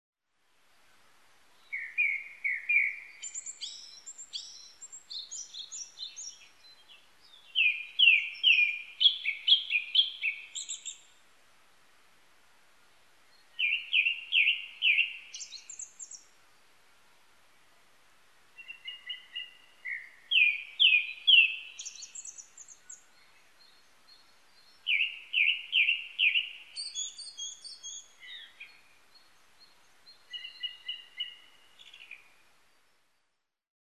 クロツグミ　Turdus cardisツグミ科
日光市稲荷川中流　alt=850m  HiFi --------------
1'51'' Windows Media Audio FILE MPEG Audio Layer3 FILE  Rec.: SONY TC-D5M
Mic.: audio-technica AT822
他の自然音：　 オオルリ・ツツドリ・ヒガラ